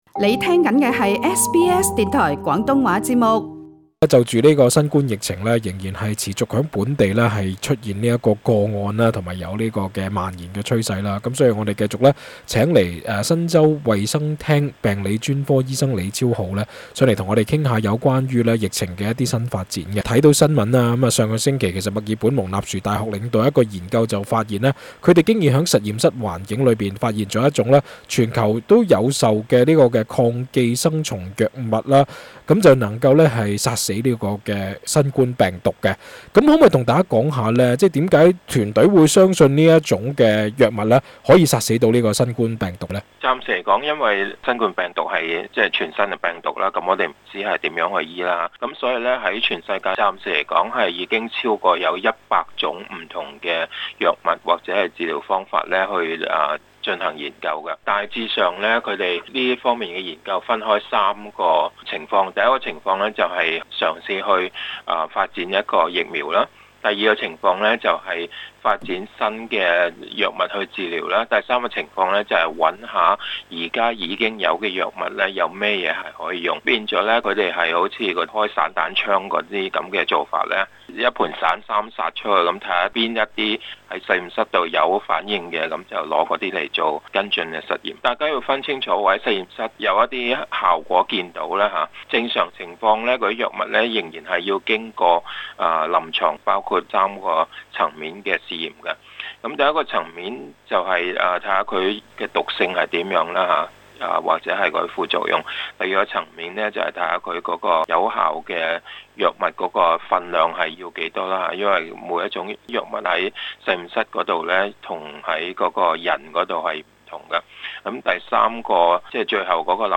更多訪問詳情，請留意本台足本錄音。